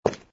fs_fr_stone04.wav